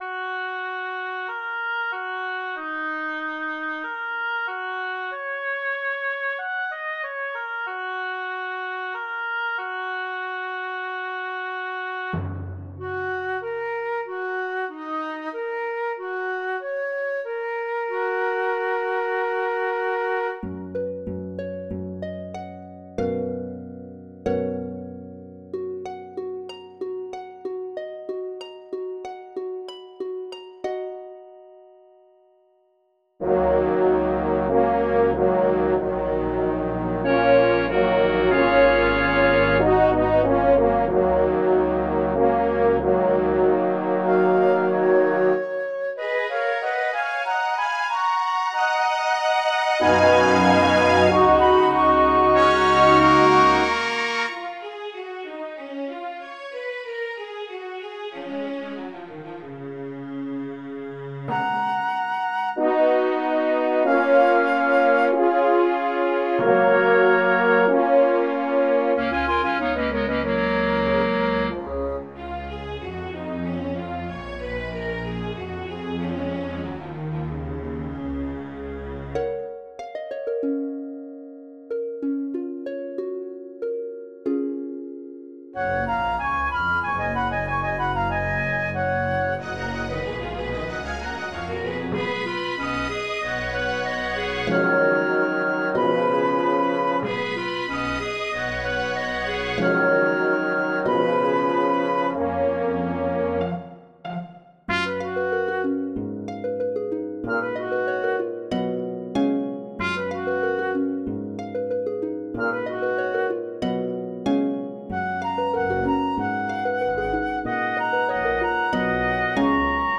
Tone Poems For Orchestra.